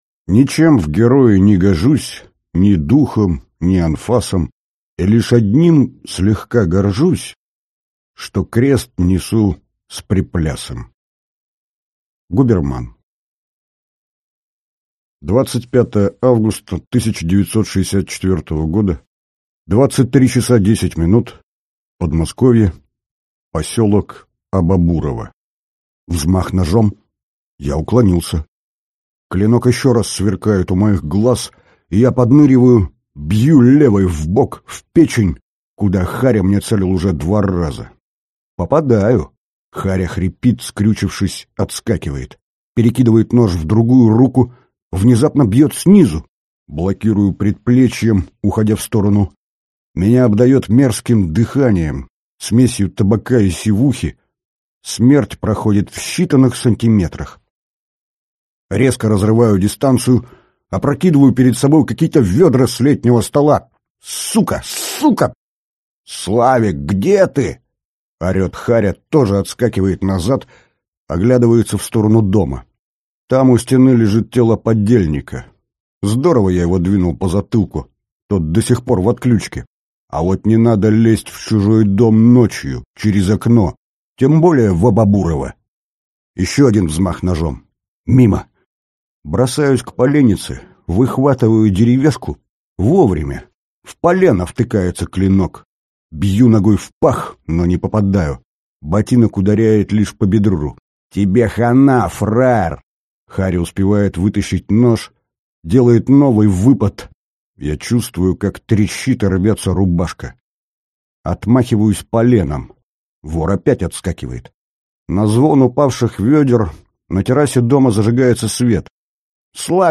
Аудиокнига Я спас СССР. Том III | Библиотека аудиокниг